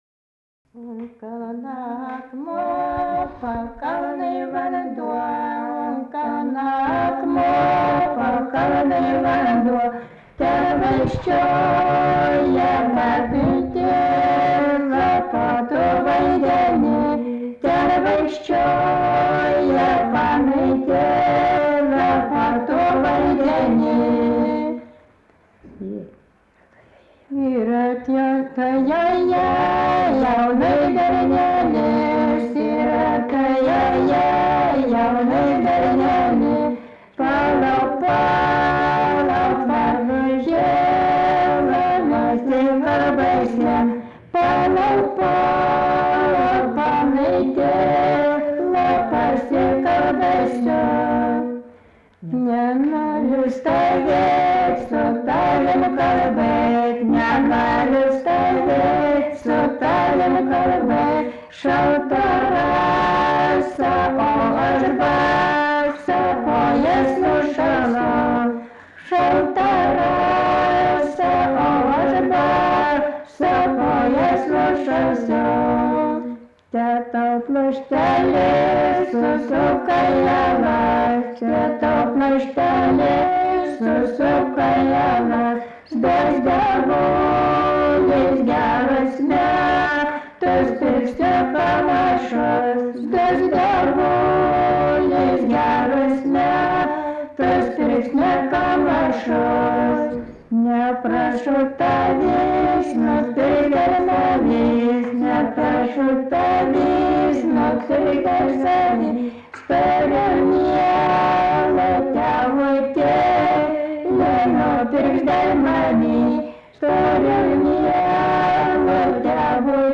daina, vestuvių
Erdvinė aprėptis Lyduokiai
Atlikimo pubūdis vokalinis